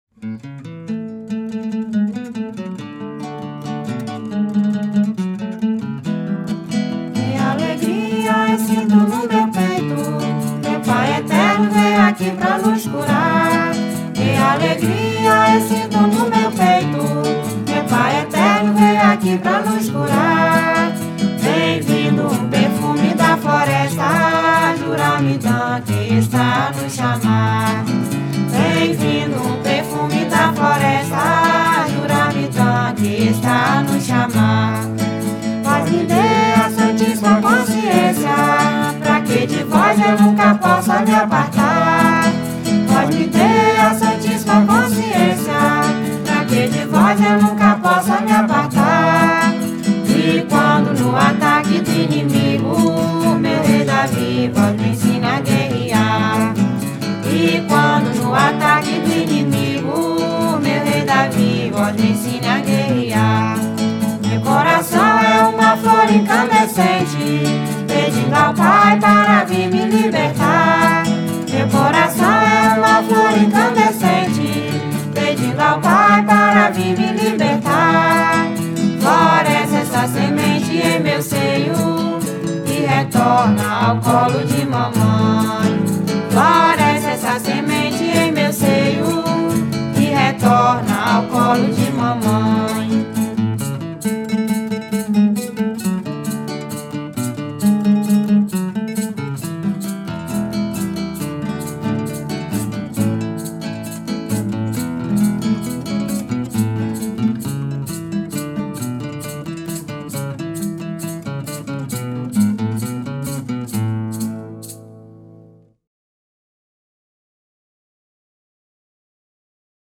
Recording source: Studio recording